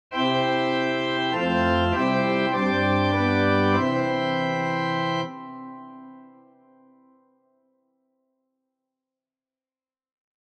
Klangbeispiel Lobet den Herren
Es wird hier allerdings zuerst nicht G-Dur gespielt, sondern erst Gsus4 oder – wie man in der klassischen Musik sagen würde – ein Quartvorhaltakkord. Dann merkt man allerdings, dass diese Quarte (C) zur Terz (G) aufgelöst wird. Danach wird also die Terz gespielt, also ein ganz normaler G-Durakkord.